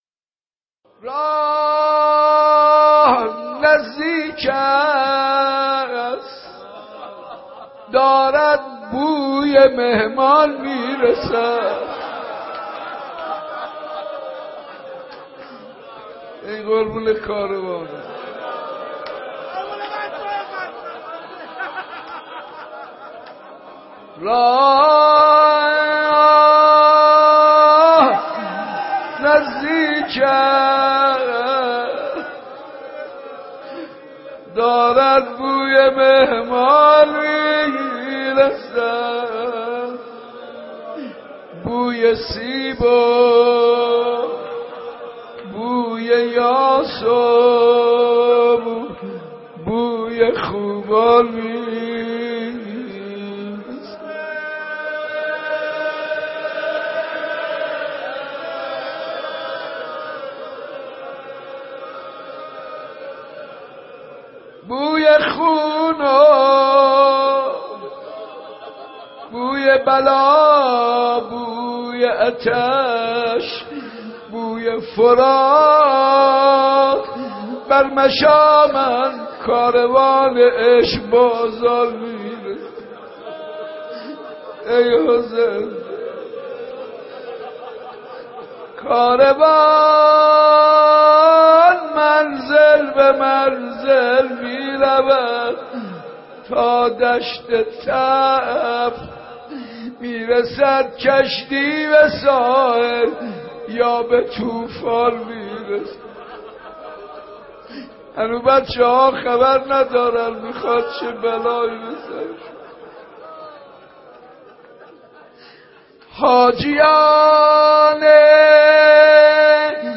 گلچین مداحی های پیشواز محرم